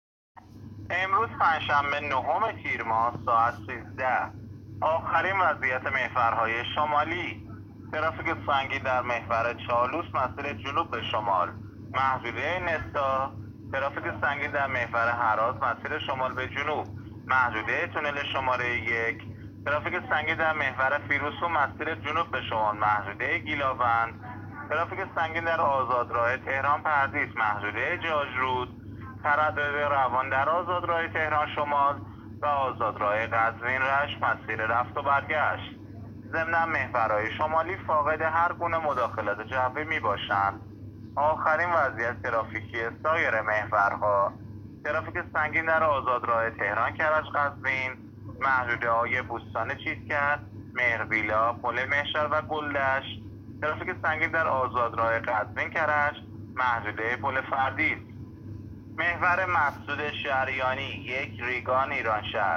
گزارش رادیو اینترنتی از آخرین وضعیت ترافیکی جاده‌ها تا ساعت ۱۳ نهم تیر؛